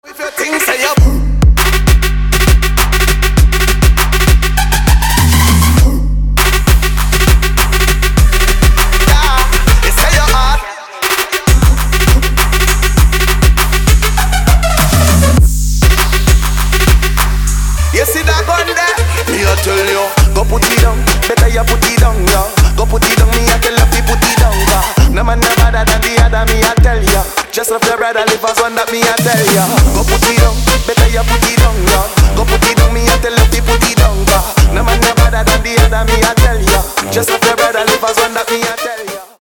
• Качество: 192, Stereo
dancehall
Trap
Reggae